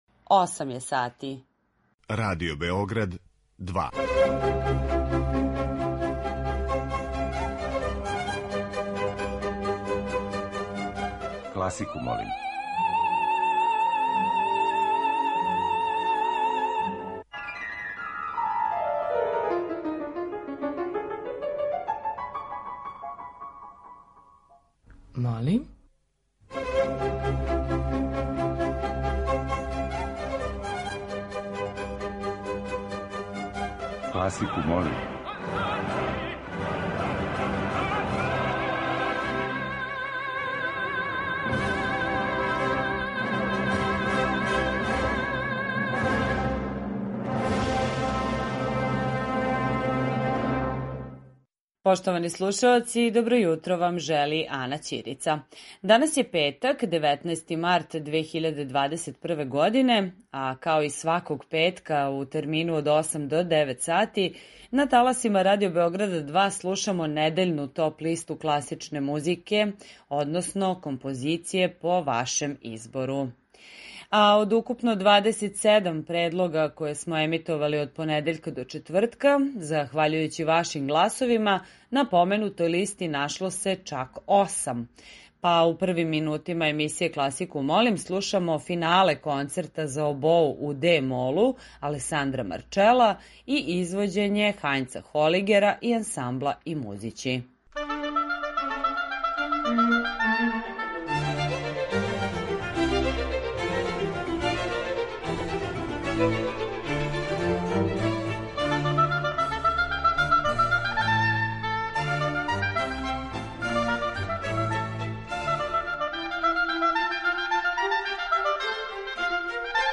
Топ-листа класичне музике